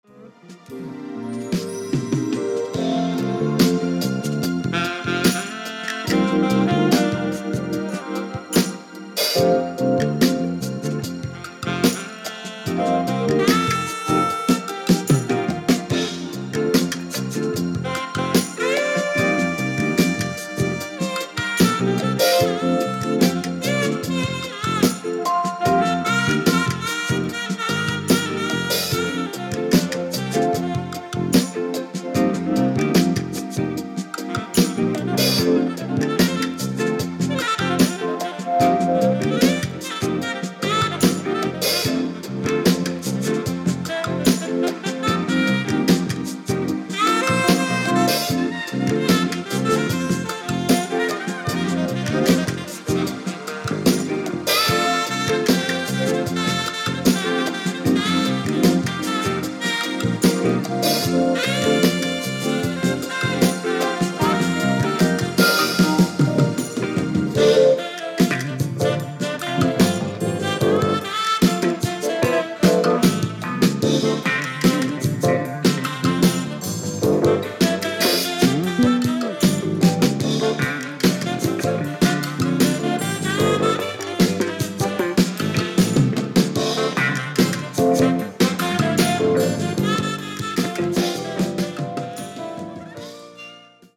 軽快なサウンドが世界的に注目されてますね！！！